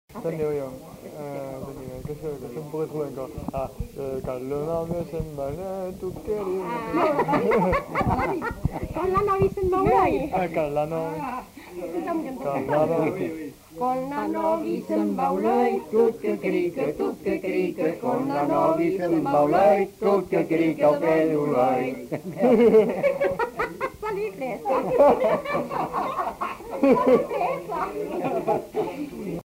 Aire culturelle : Grandes-Landes
Lieu : Luxey
Genre : chant
Type de voix : voix mixtes
Production du son : chanté
Danse : rondeau